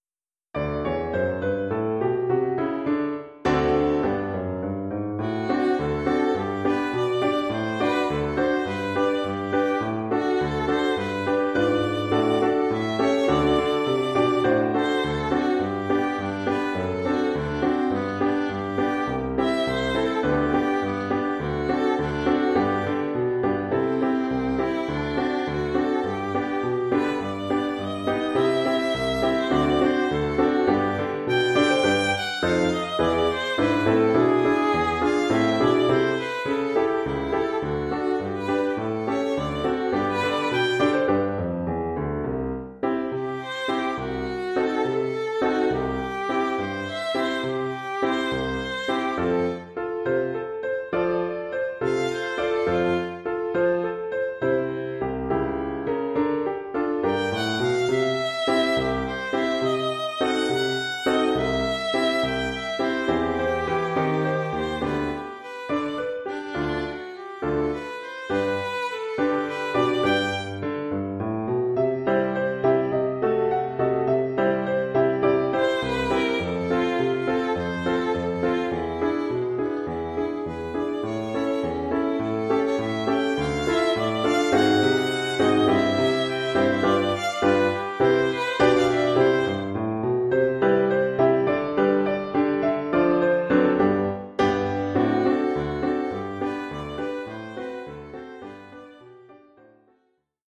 Oeuvre pour violon et piano.